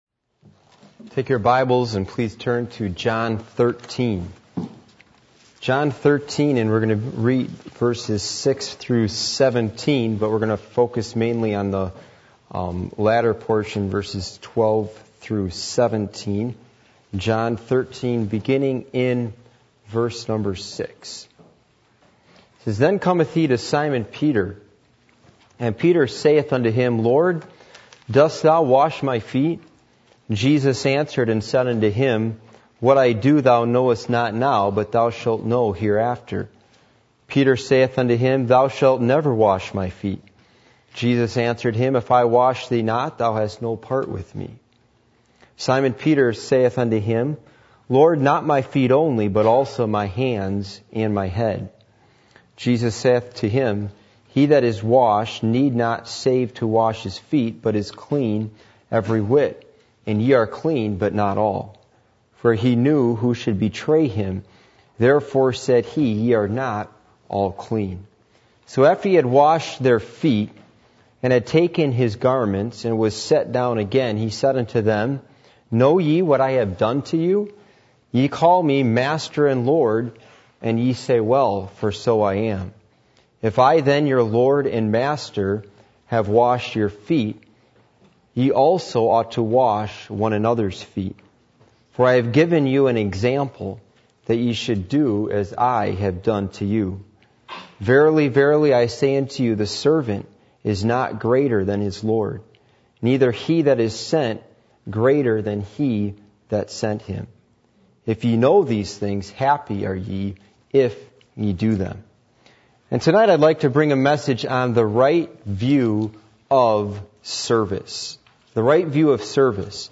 John 13:6-17 Service Type: Midweek Meeting %todo_render% « Principles Of Clothing As Taught In The Bible How Long Shall It Be?